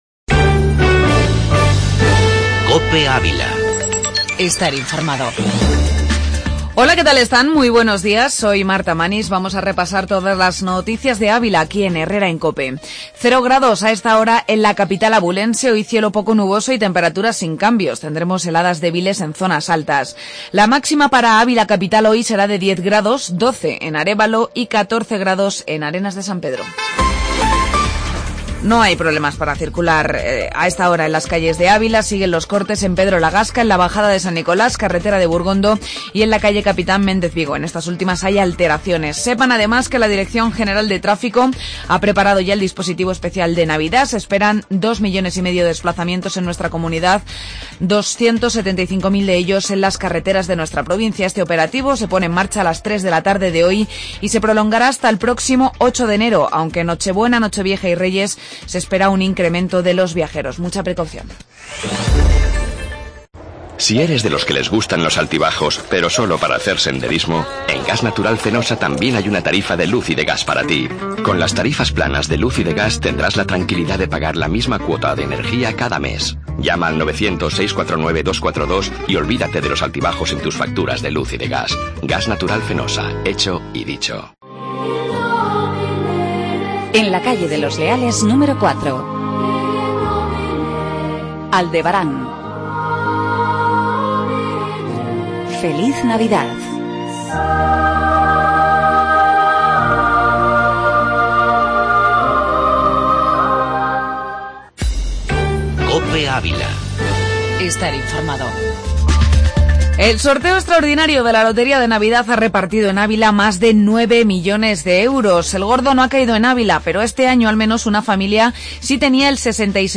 Informativo La Mañana en Ávila